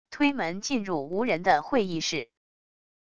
推门进入无人的会议室wav音频